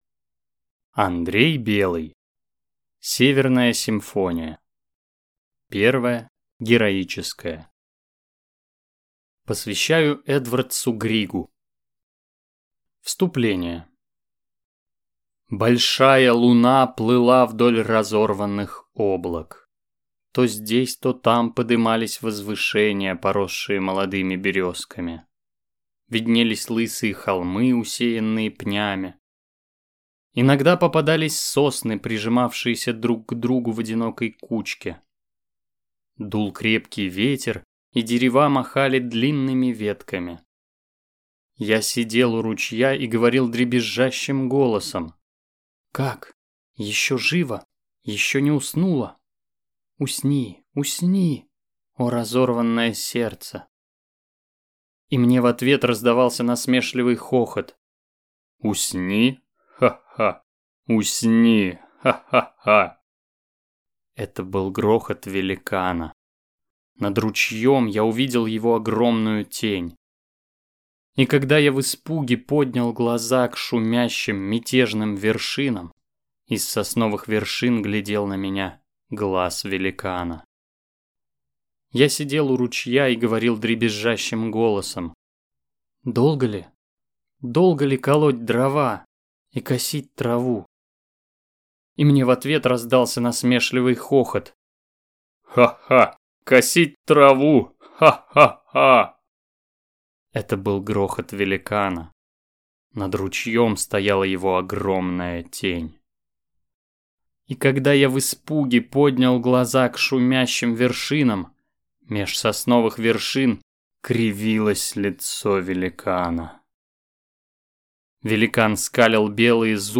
Aудиокнига Северная симфония